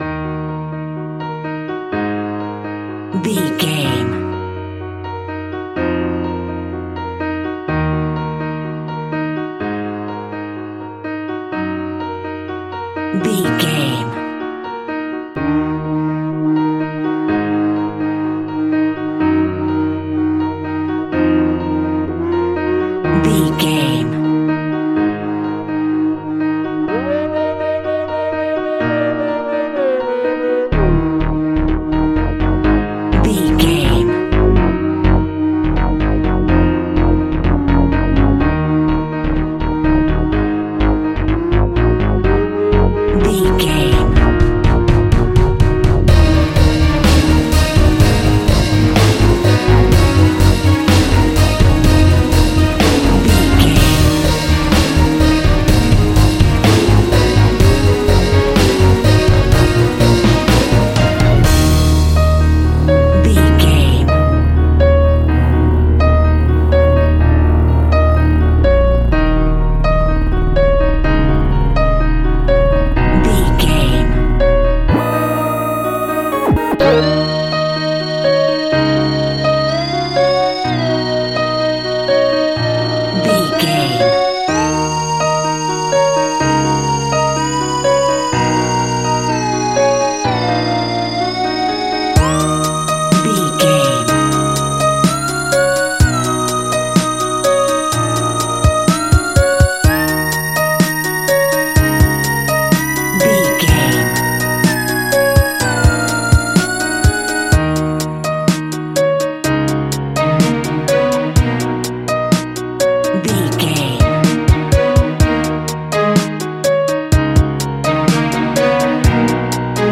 Transylvania Horror.
Aeolian/Minor
E♭
tension
ominous
dark
eerie
synthesizer
piano
drums
Synth Pads
atmospheres